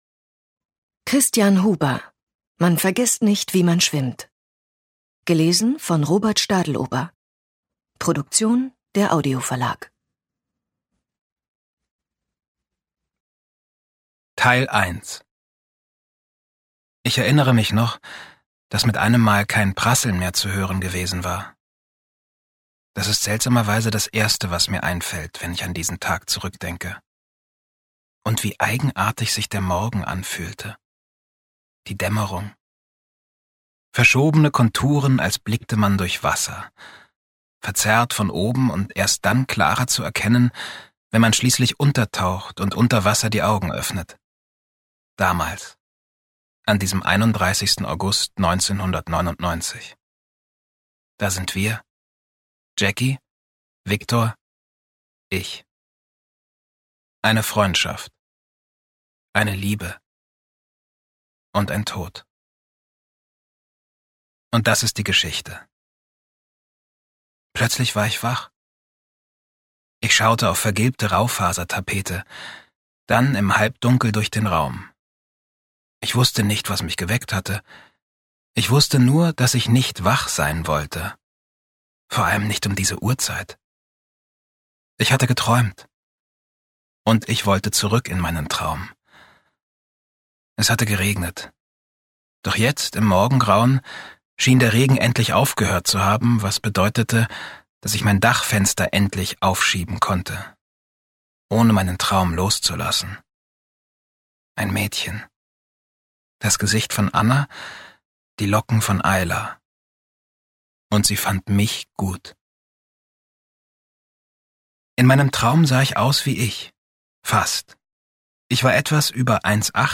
Robert Stadlober (Sprecher)